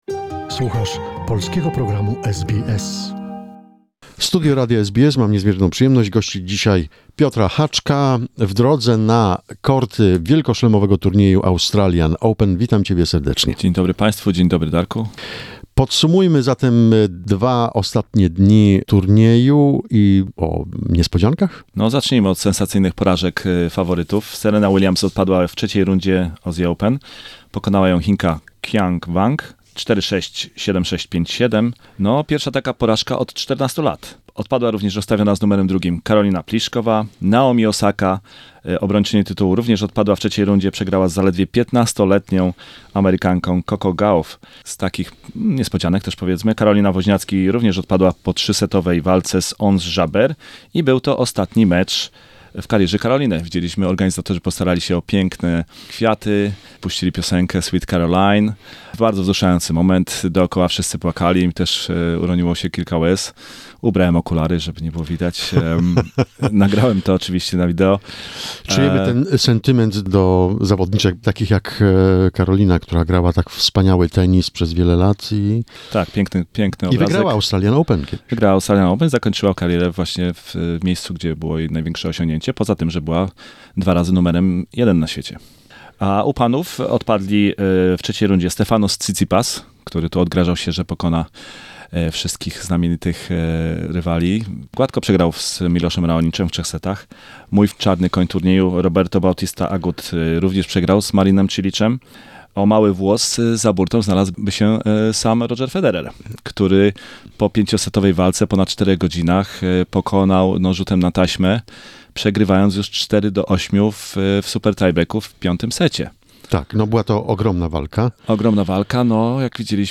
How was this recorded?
in SBS studio , Melbourne